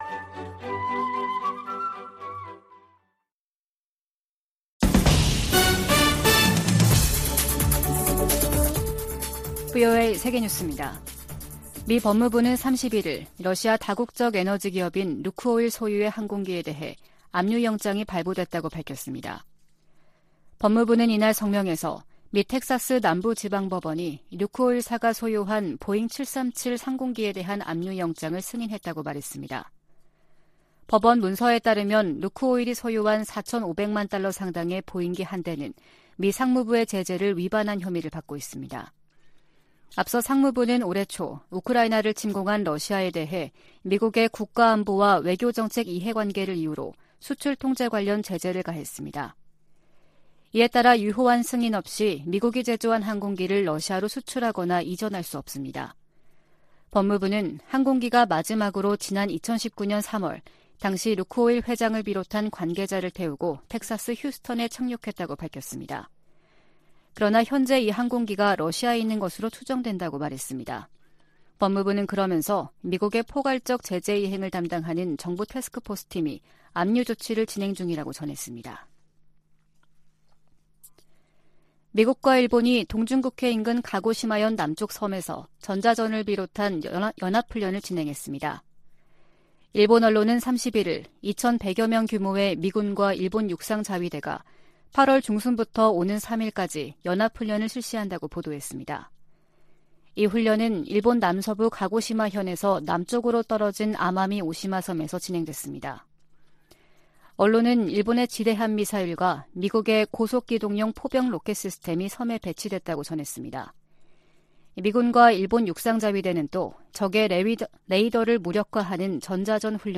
VOA 한국어 아침 뉴스 프로그램 '워싱턴 뉴스 광장' 2022년 9월 2일 방송입니다. 한국에서 실시된 미한 연합군사연습, 을지프리덤실드(UFS)가 1일 끝났습니다. 미 국방부 부장관이 북한을 미국이 직면한 중대한 위협 가운데 하나로 지목하고, 도전에 맞서기 위한 기술 혁신이 필수적이라고 강조했습니다. 1일 하와이에서 열리는 미한일 안보수장 회동에서는 북한의 추가 도발 가능성에 대한 공조 방안이 집중 논의될 것이라고 미 전직 관리들은 전망했습니다.